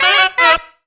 FAST SAXES.wav